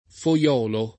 [ fo L0 lo ]